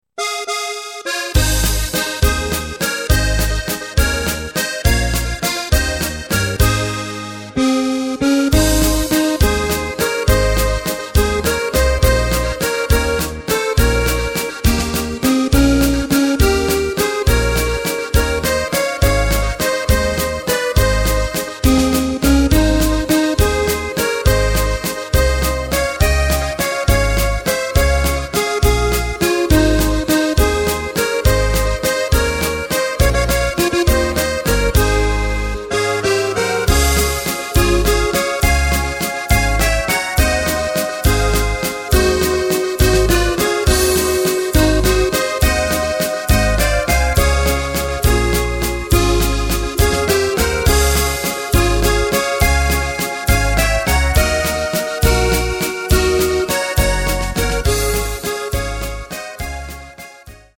Takt:          3/4
Tempo:         206.00
Tonart:            Ab
Walzer Schweiz aus dem Jahr 2018!